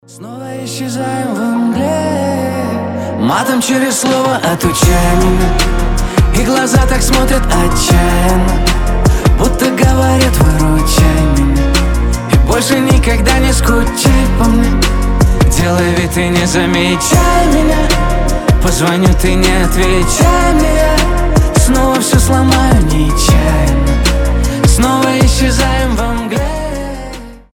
поп
красивый мужской голос